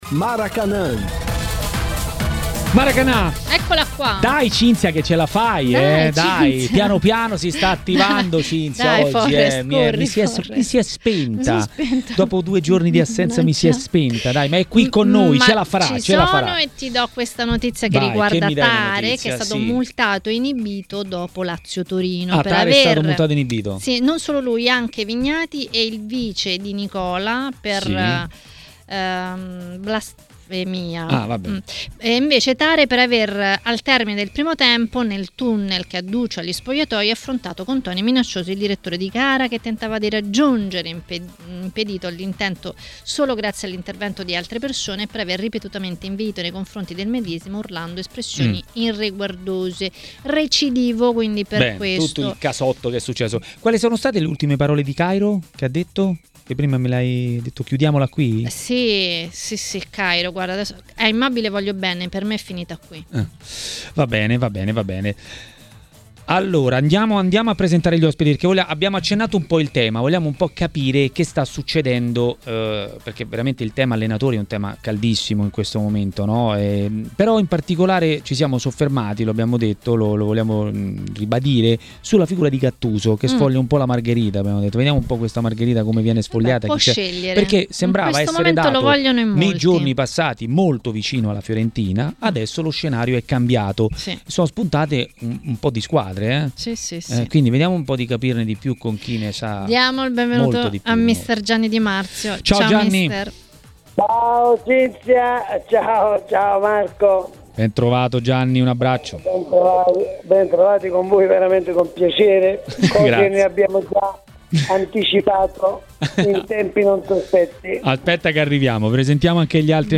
A commentare le notizie di giornata a Maracanà, nel pomeriggio di TMW Radio, è stato mister Gianni Di Marzio.